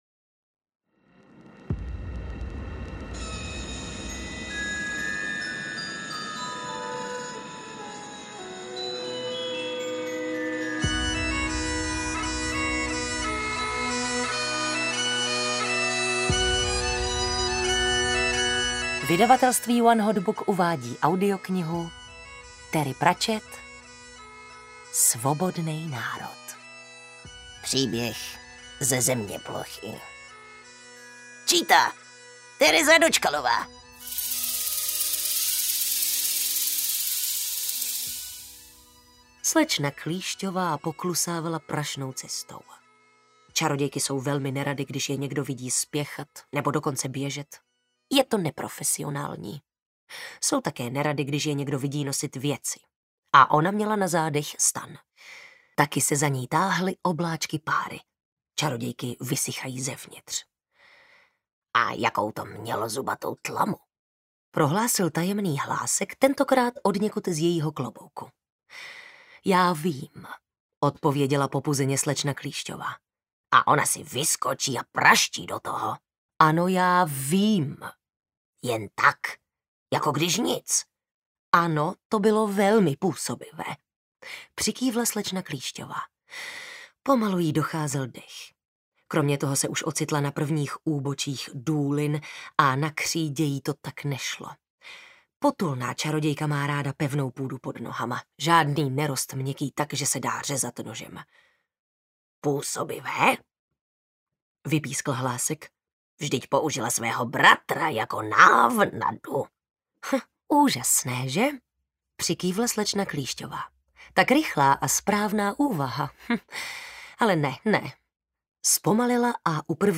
Svobodnej národ audiokniha
Ukázka z knihy